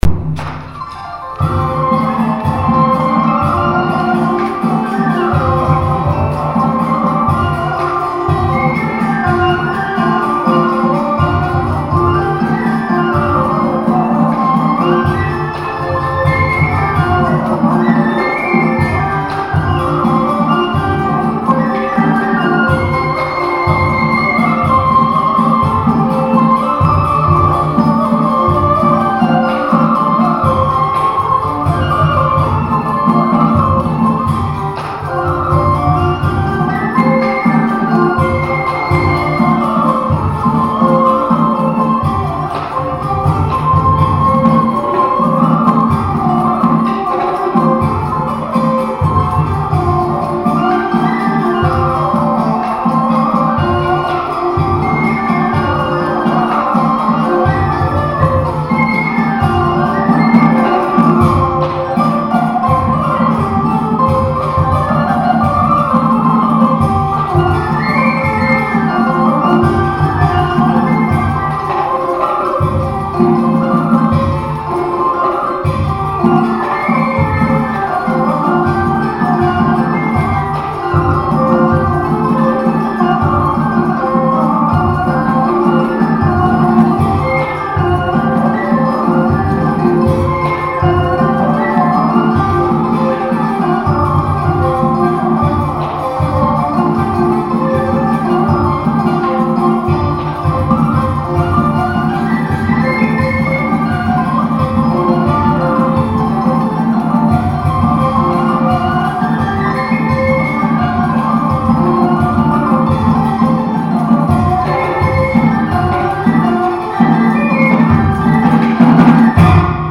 Un petit dernier fichier pour terminer en beauté, juste une musique jouée lorsque je suis allé au spectacle de marionnettes sur l'eau à Hanoi...
Musique marionettes sur eau, Hanoi.MP3